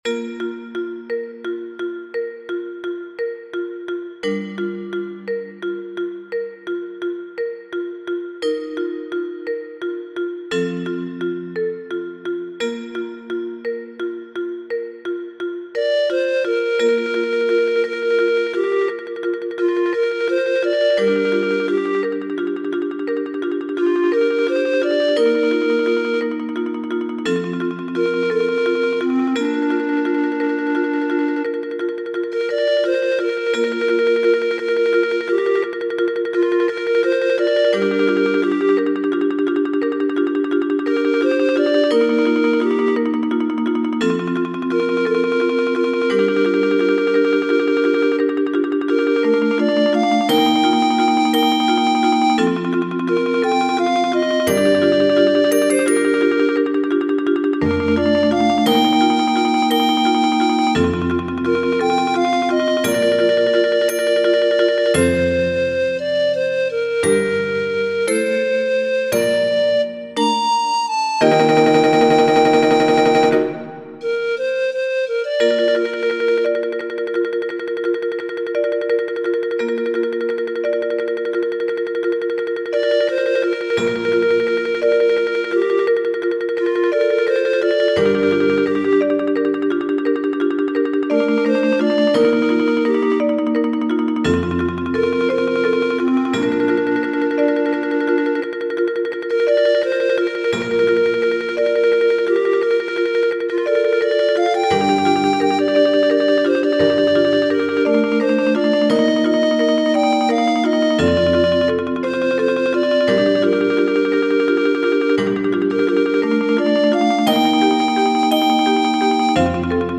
Mallet-Steelband Muziek
Panfluit Klokkenspel Vibrafoon Marimba Timpani